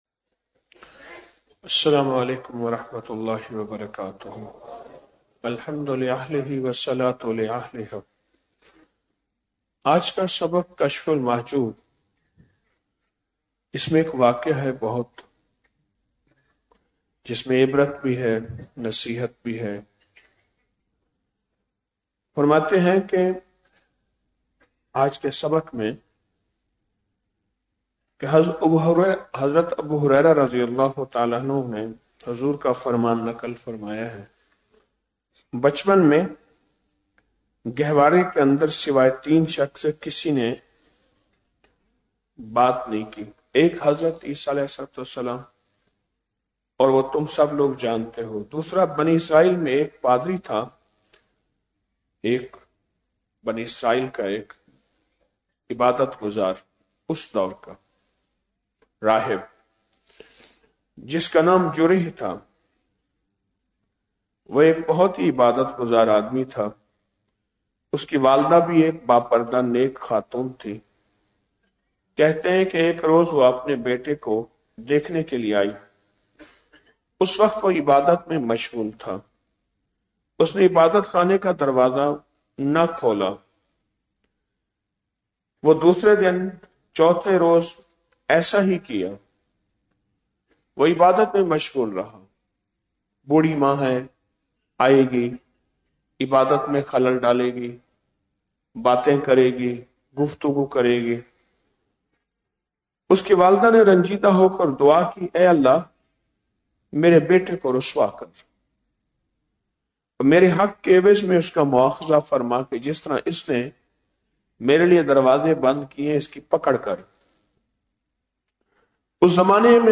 غیب کا اقرار مشاہدے کا انکار || 17 رمضان المبارک بعد نماز فجر- 07 مارچ 2026ء